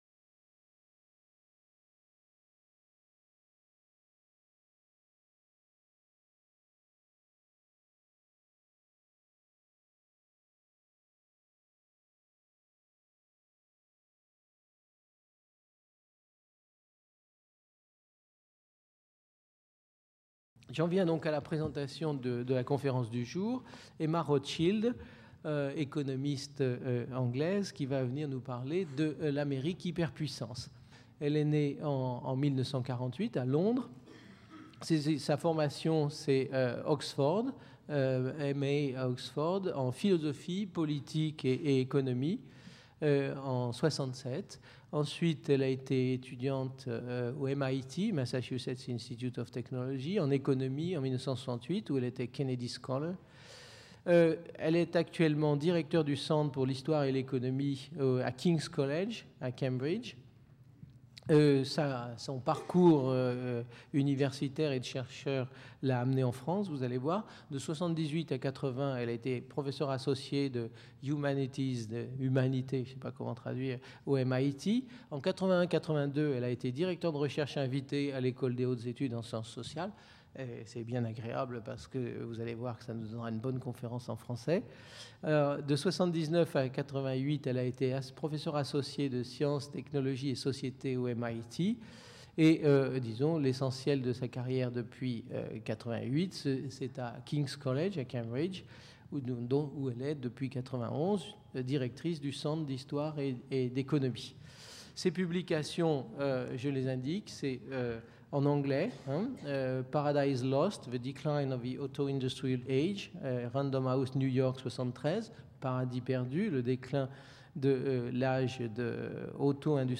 Cette conférence portera sur les États-Unis et sur l'histoire de la mondialisation. L'indépendance des États-Unis fut le produit d'une période durant laquelle la politique s'intéressait avec ferveur à l'idée de mondialisation.